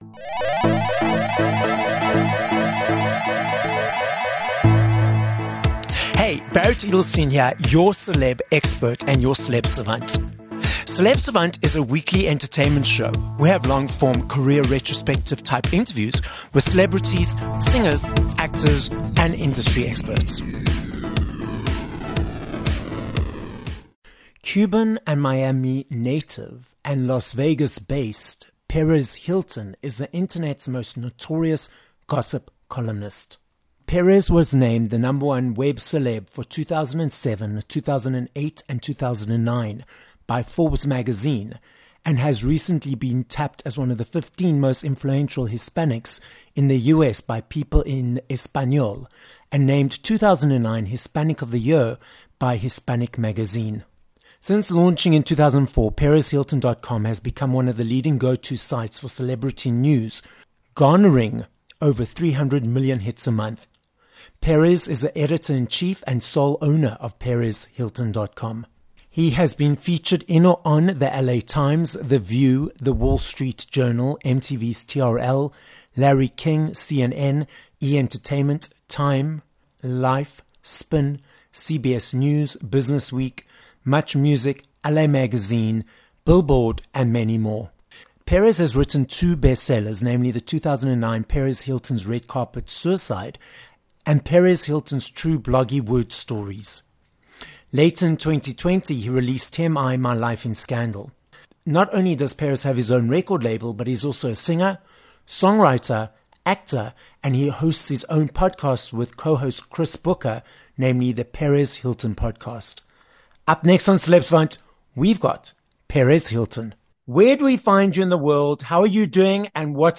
19 Oct Intervlew with Perez Hilton